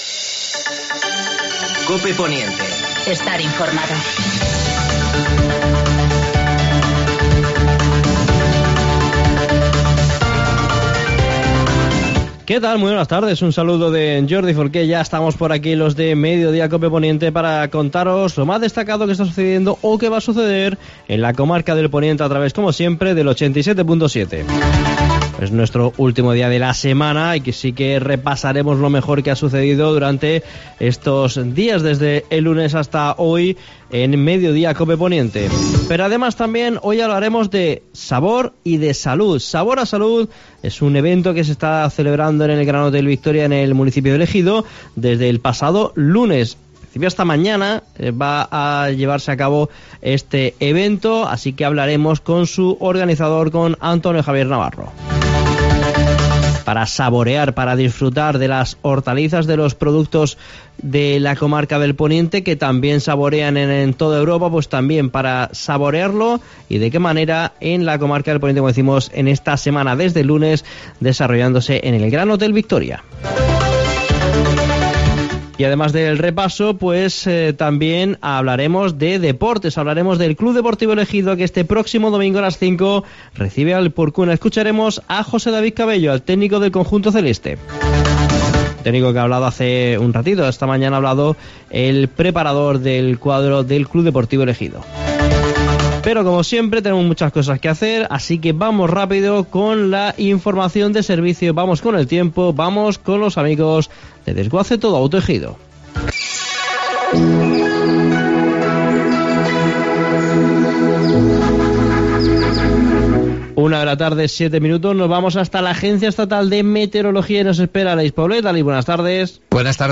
Actualidad en el Poniente. Entrevista